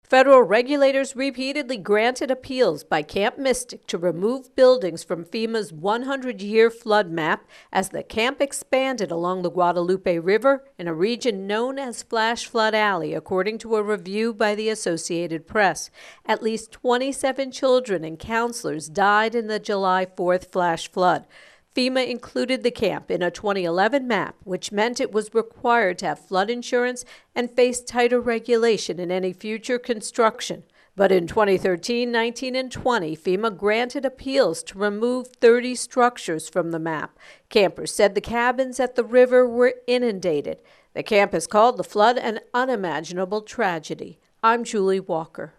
AP correspondent